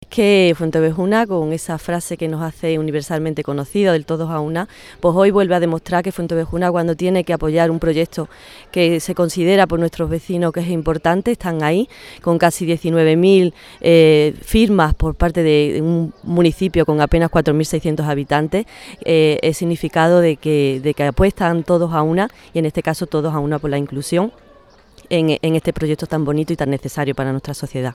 La alcaldesa de Fuente Obejuna, Silvia Mellado, y el presidente del Grupo Social ONCE, Miguel Carballeda, participaron junto al director general de Personas con Discapacidad de la Junta de Andalucía, Pedro Calbó, en el descubrimiento de la placa de la nueva calle en un acto festivo al que asistieron cientos de estudiantes de la localidad cordobesa que disfrutaron de una alegre jornada en la que no faltaron los globos y las charangas.
En una plaza 'Lope de Vega' abarrotada de público infantil, con todos los alumnos de los centros educativos de Fuente Obejuna, que hicieron un alto en sus clases para asistir al acto,